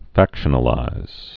(făkshə-nə-līz)